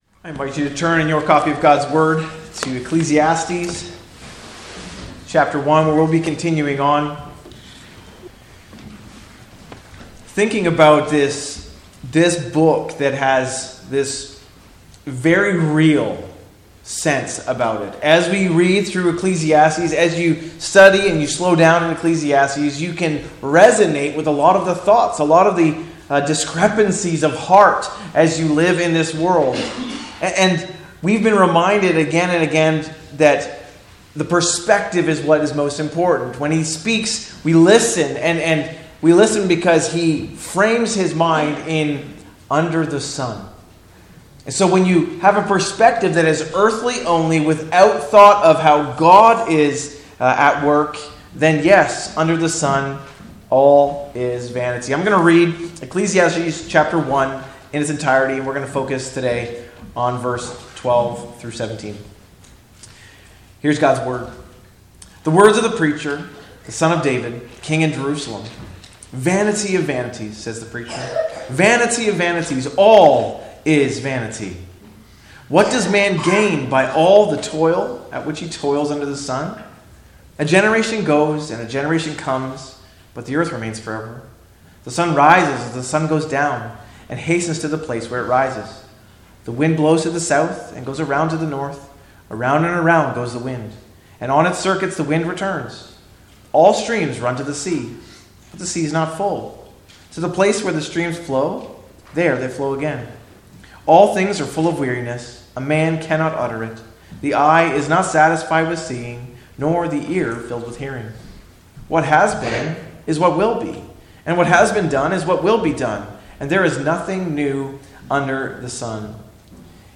Sermons | West Lorne Baptist Church